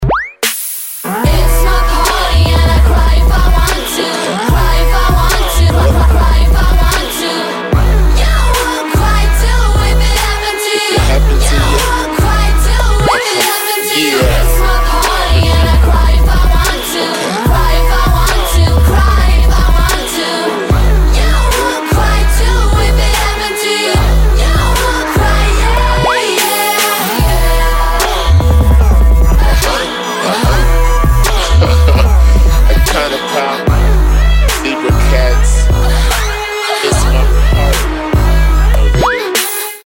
• Качество: 192, Stereo
поп
женский вокал
dance
Electronic